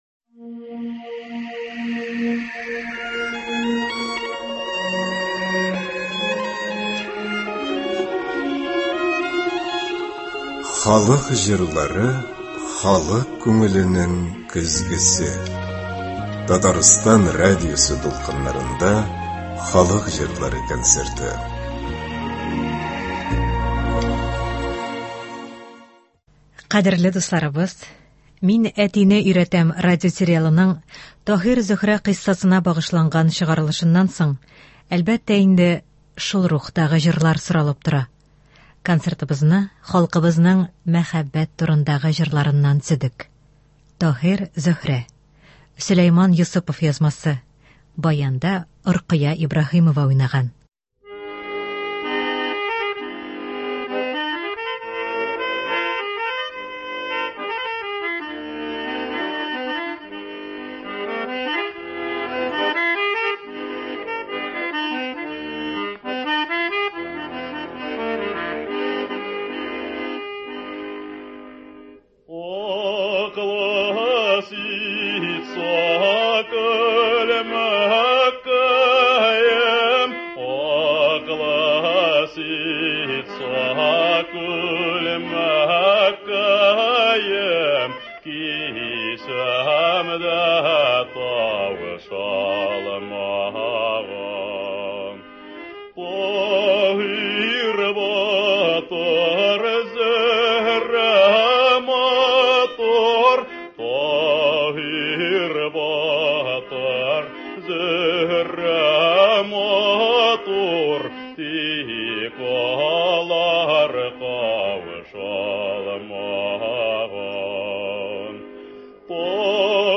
Татар халык көйләре (24.06.23)